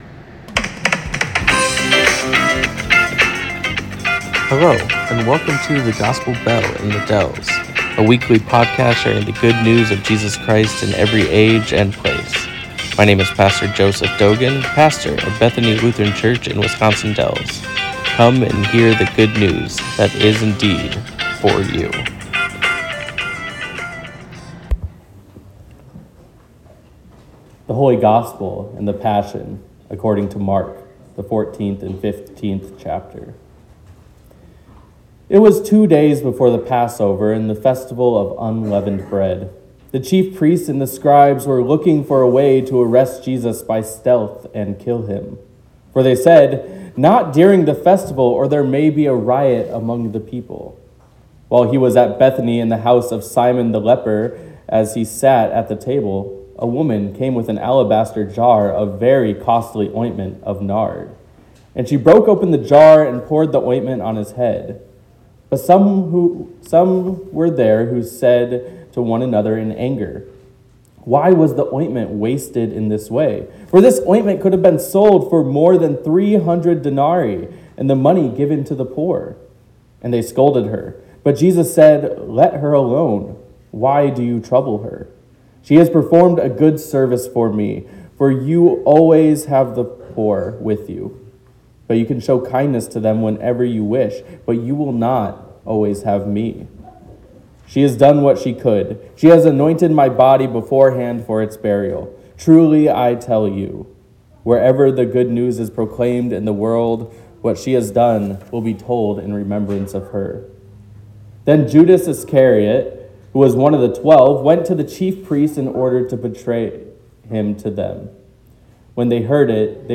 The reading of the Passion story from Mark